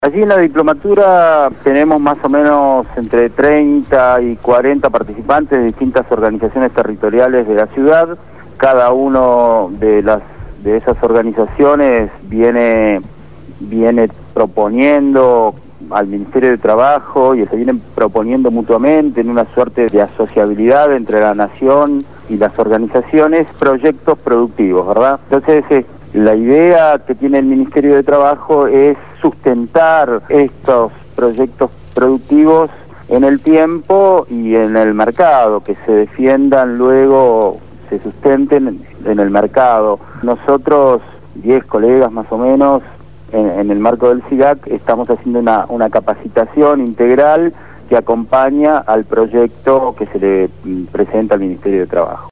COLUMNA DEL CENTRO DE INNOVACIÓN Y DESARROLLO PARA LA ACCIÓN COMUNITARIA (CIDAC) DE BARRACAS EN RADIO GRÁFICA FM 89.3